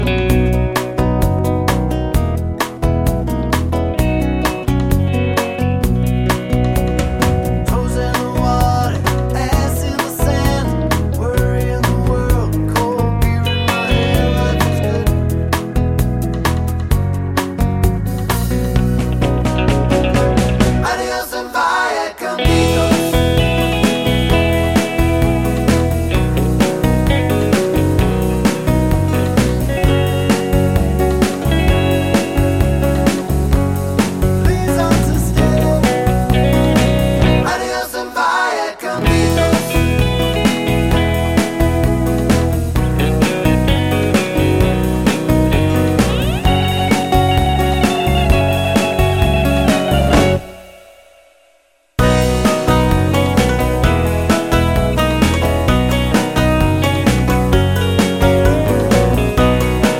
no Backing Vocals Country (Male) 4:18 Buy £1.50